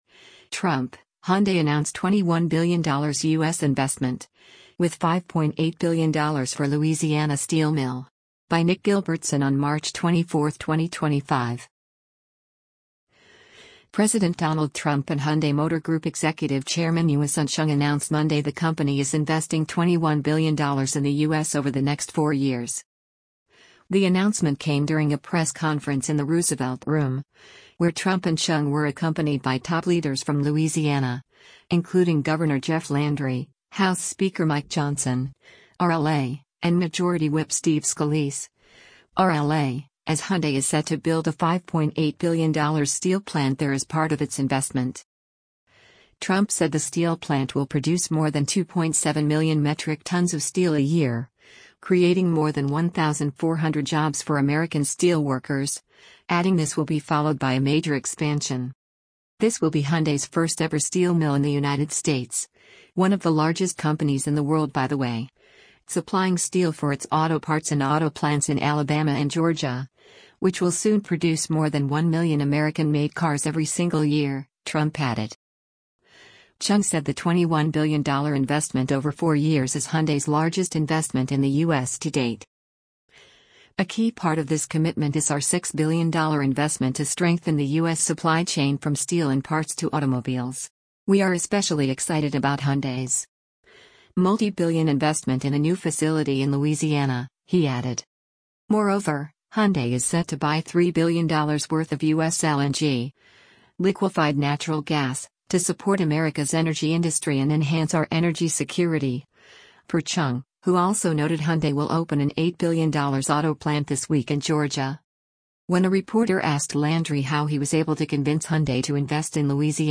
The announcement came during a press conference in the Roosevelt Room, where Trump and Chung were accompanied by top leaders from Louisiana, including Gov. Jeff Landry, House Speaker Mike Johnson (R-LA), and Majority Whip Steve Scalise (R-LA), as Hyundai is set to build a $5.8 billion steel plant there as part of its investment.